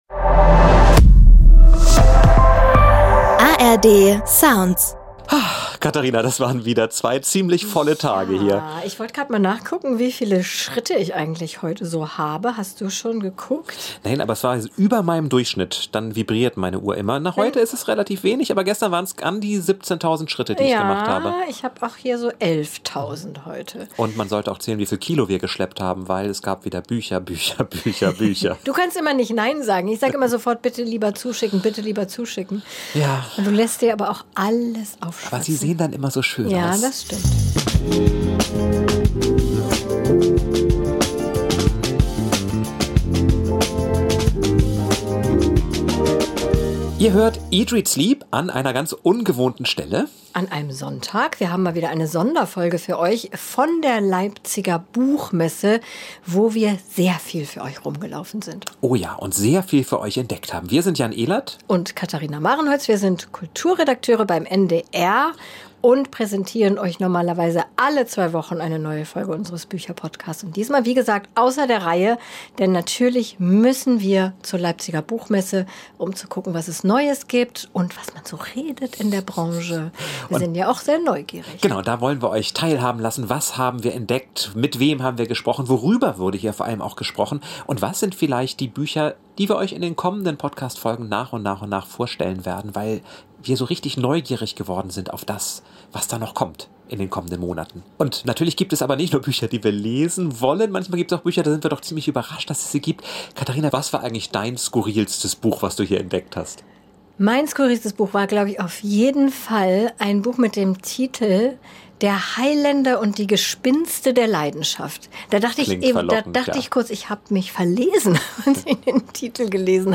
Und dann wackelt und ruckelt plötzlich bei der Aufnahme dieser Folge am Freitagabend das mobile Studio, das in einem LKW untergebracht ist.